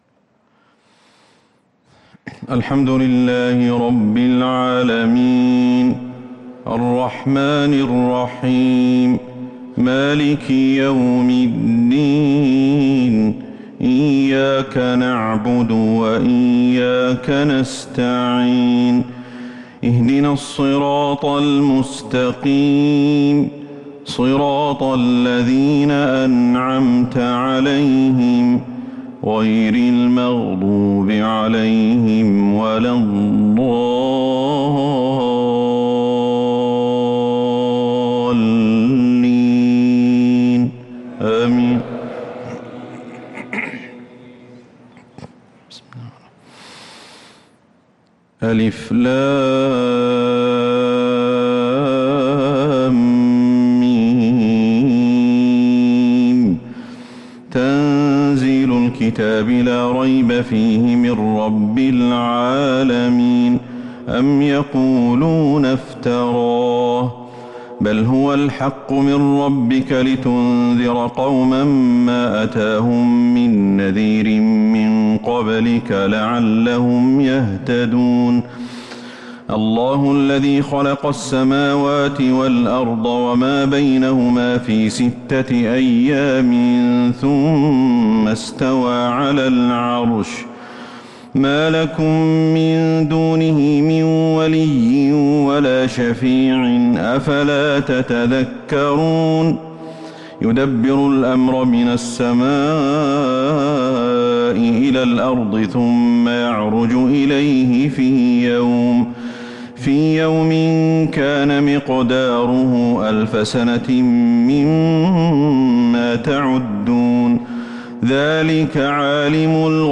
صلاة الفجر للقارئ أحمد الحذيفي 10 ذو القعدة 1443 هـ